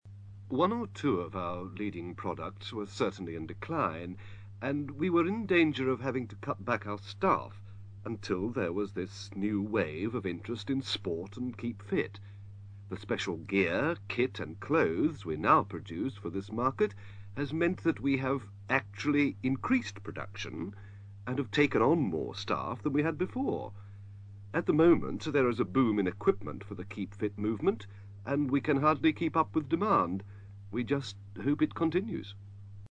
ACTIVITY 185: You are going to hear people from different professions talking about the boom in the 'Keep-Fit' industry.